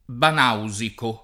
banausico
banausico [ ban # u @ iko ] agg.; pl. m. ‑ci